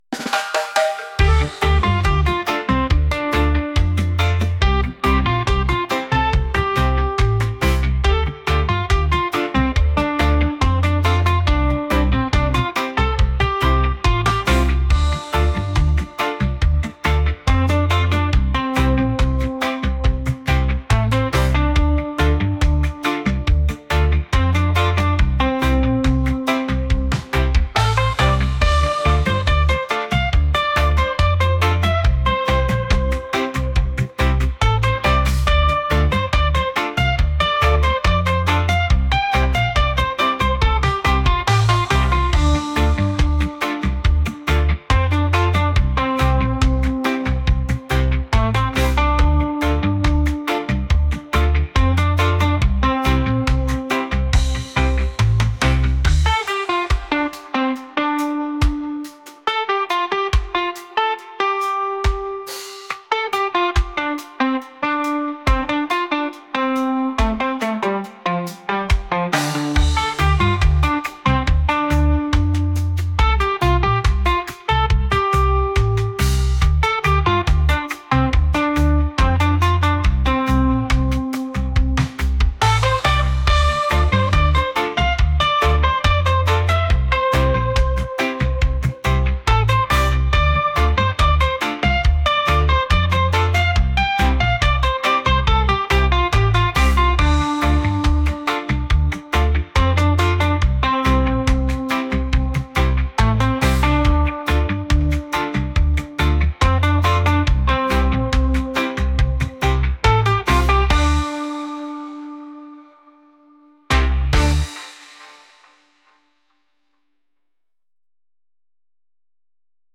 reggae | funk | world